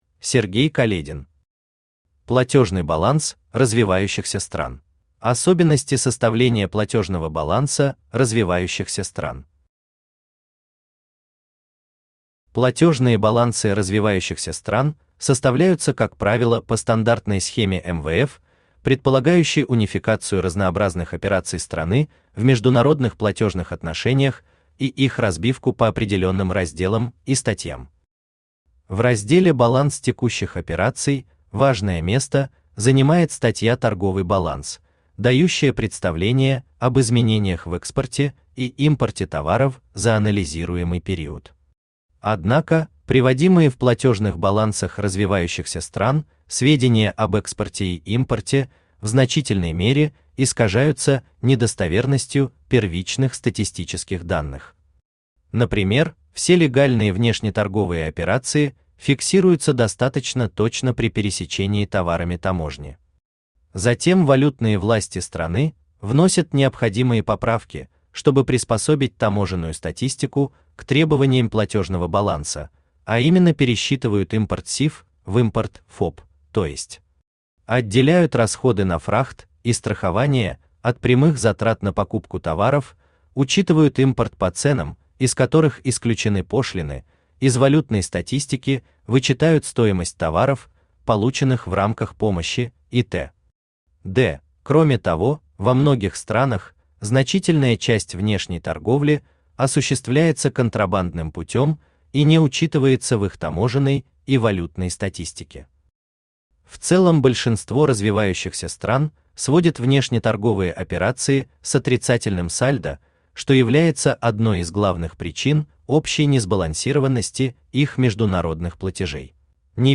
Aудиокнига Платёжный баланс развивающихся стран Автор Сергей Каледин Читает аудиокнигу Авточтец ЛитРес.